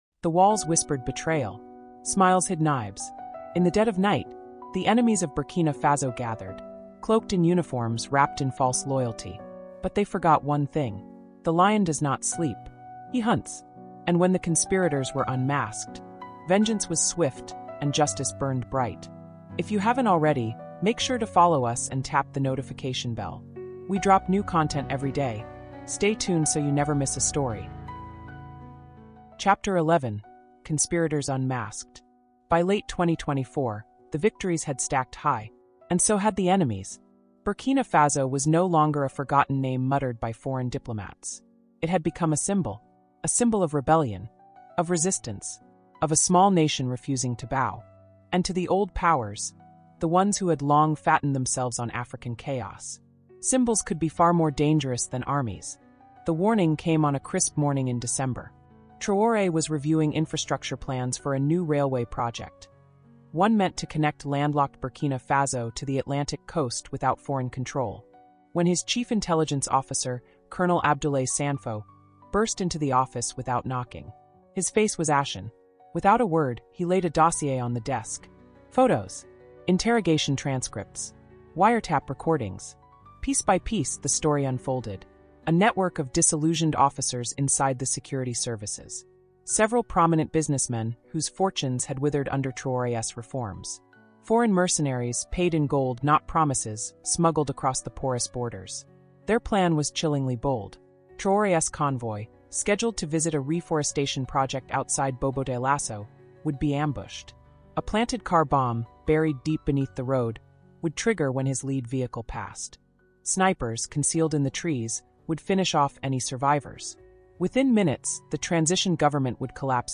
Captain Ibrahim Traoré: africa cultural diplomacy (ch11)| Audiobook